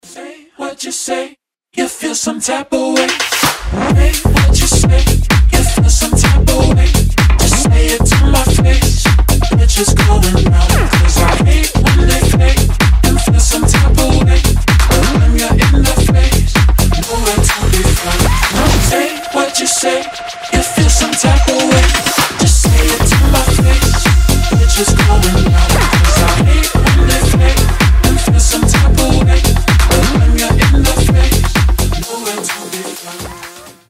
атмосферные
EDM
басы
Bass House
качающие
Tech House
четкие